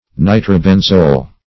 Search Result for " nitrobenzole" : The Collaborative International Dictionary of English v.0.48: Nitrobenzol \Ni`tro*ben"zol\, Nitrobenzole \Ni`tro*ben"zole\, (? or ?), n. See Nitrobenzene .